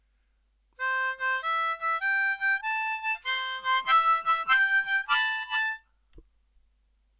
Add some tongue slaps to the octaves for extra crunch.
Over the IV chord